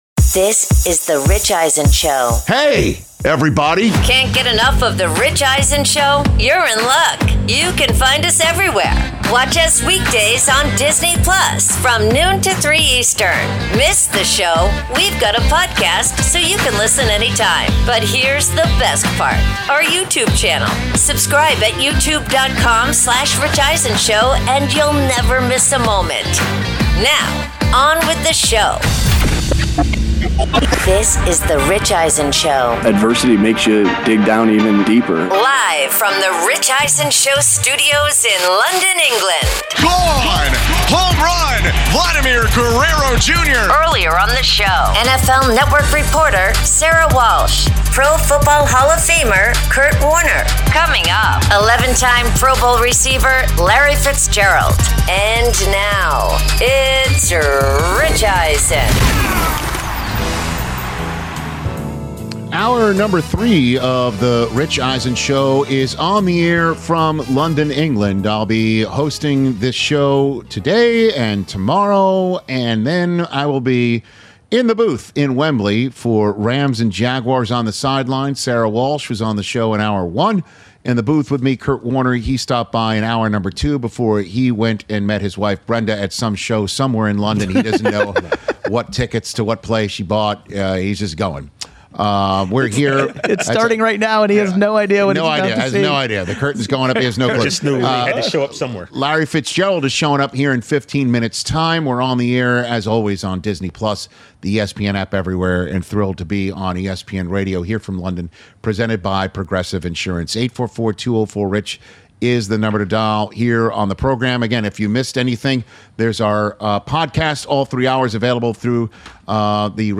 Hour 3: Live from London with NFL Power Rankings, ‘Higher Register’ plus Larry Fitzgerald Podcast with Rich Eisen